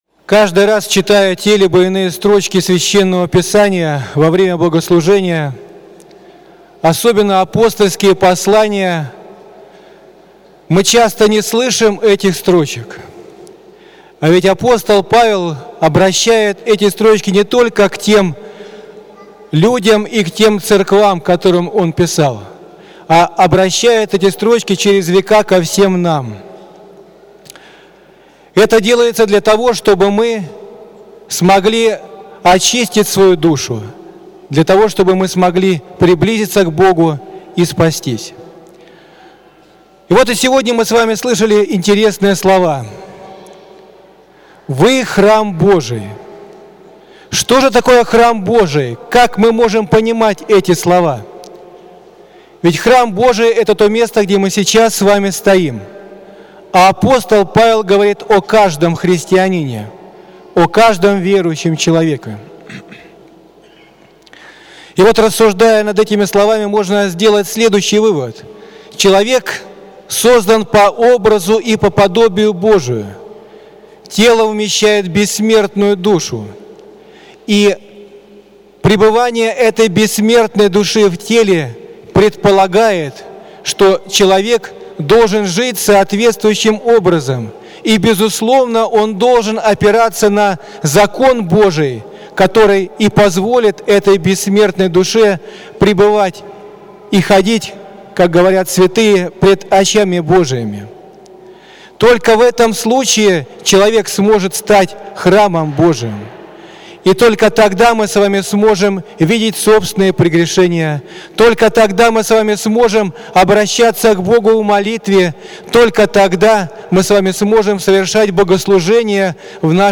Евангельское чтение и проповедь на Литургии в неделю 17-ю по Пятидесятнице
Проповедь